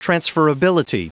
Prononciation du mot transferability en anglais (fichier audio)
Prononciation du mot : transferability